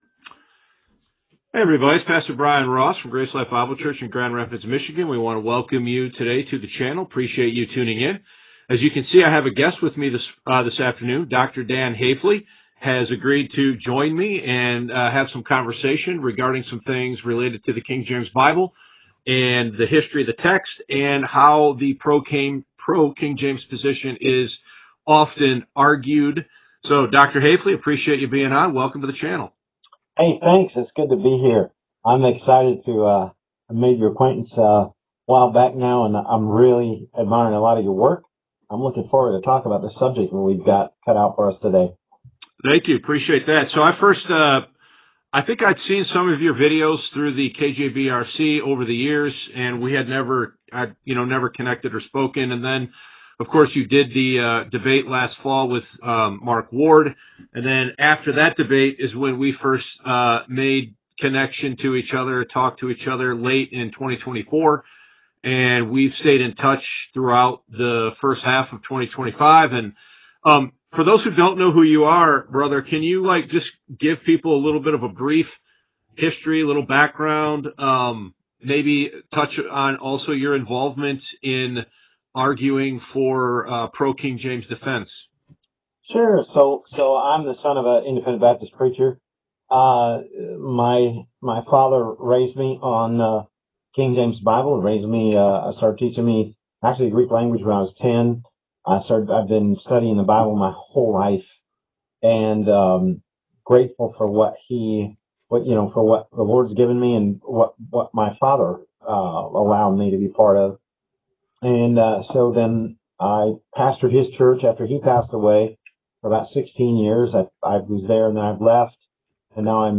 The Two Steams Of Bibles Paradigm Of Transmission: A Conversation